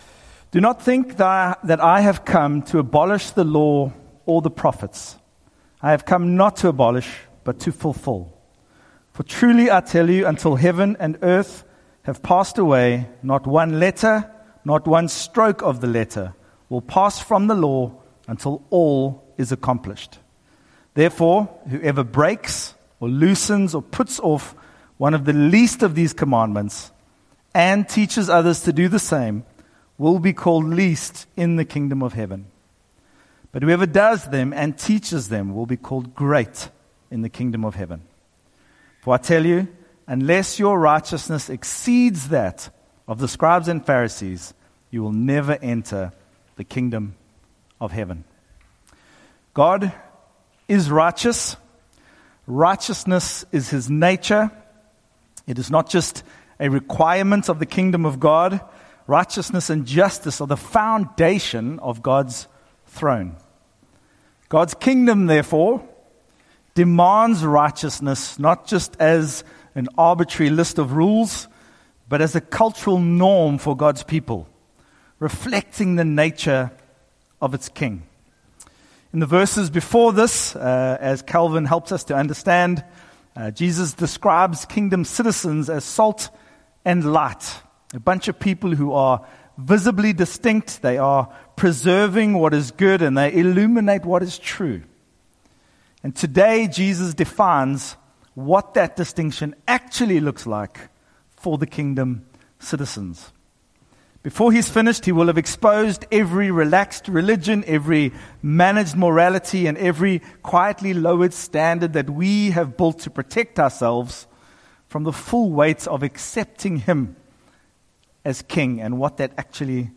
SERMON: Kingdom Righteousness – Part 1
" Kingdom of Heaven 2025/2026 " Morning Service Facebook Tweet Link Share Link Send Email Powered by Series Engine